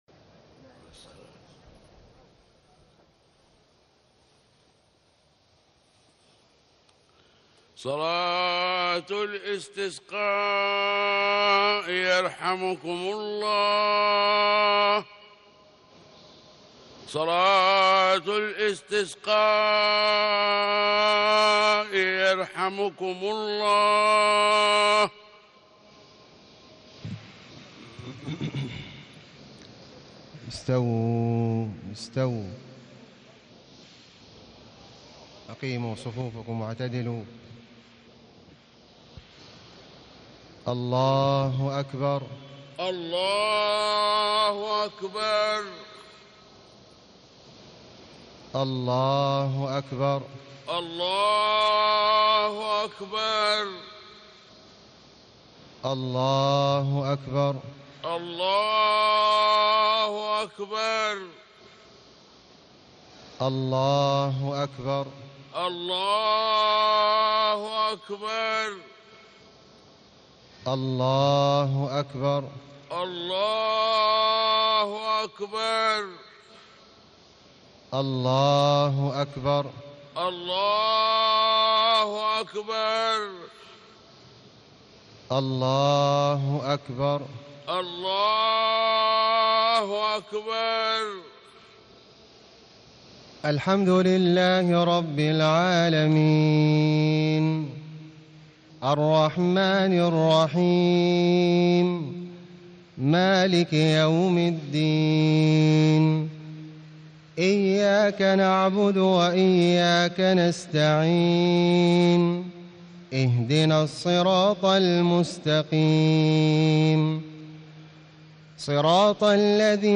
صلاة الإستسقاء 4-4-1437هـ سورتي الأعلى و الغاشية > 1437 🕋 > الفروض - تلاوات الحرمين